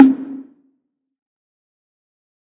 Batmans DarkSoul Perc 13.wav